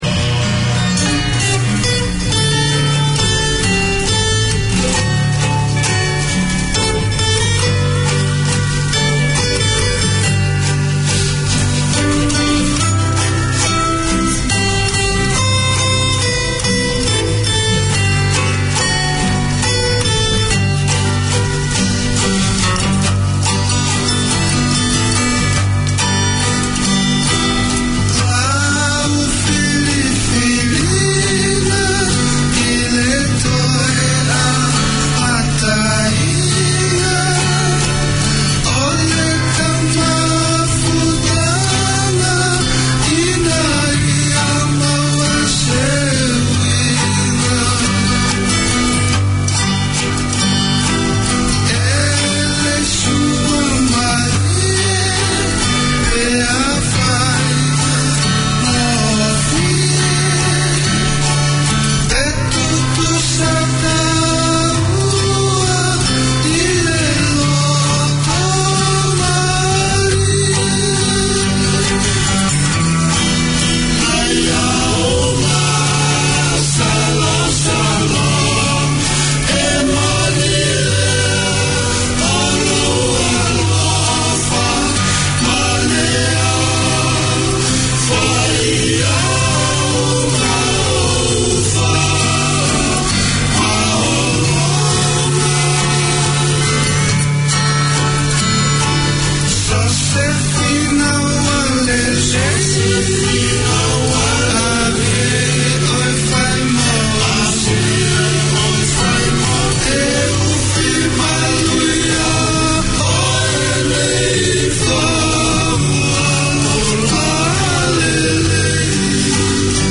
Here’s the show that talks to young people and their parents about being young and Tongan in NZ. A chance for the generations to dialogue, hear what’s happening to and for youth in the community and hear Tongan music, traditional and modern.